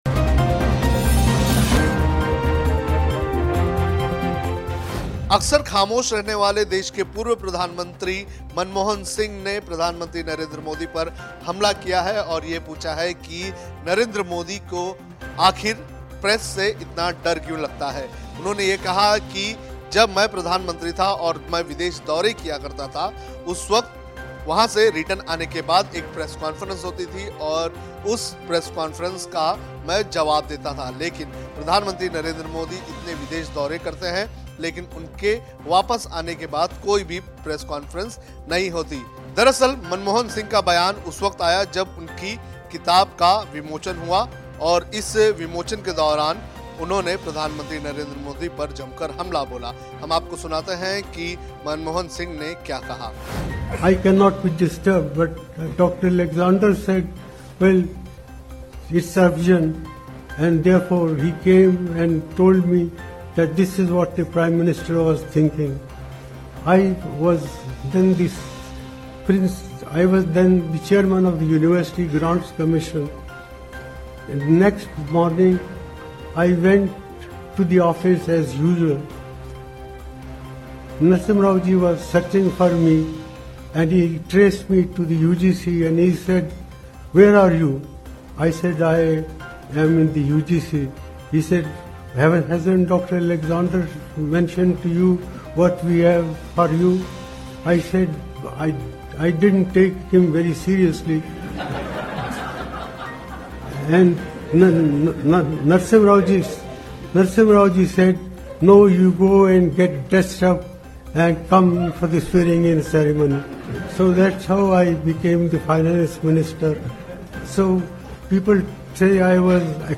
न्यूज़ रिपोर्ट - News Report Hindi / मनमोहन का मोदी पर हमला, प्रधानमंत्री रहते वक़्त प्रेस से नहीं लगता था डर